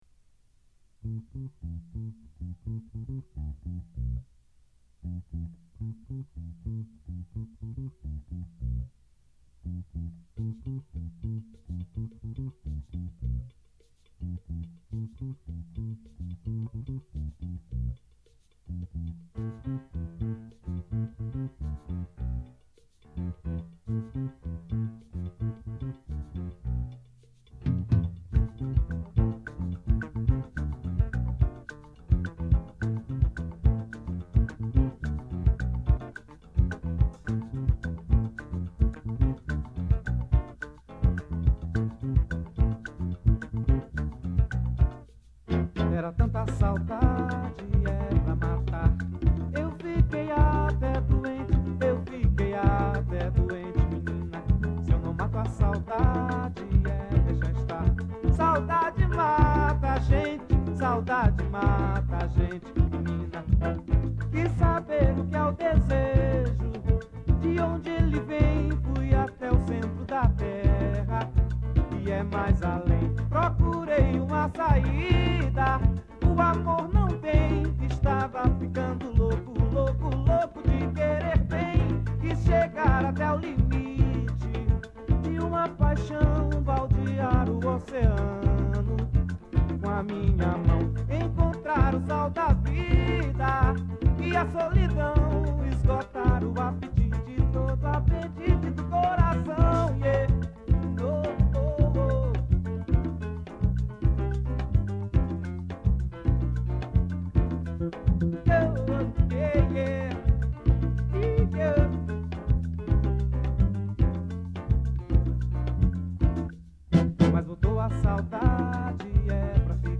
Type: Gravação musical Subject
Home recordings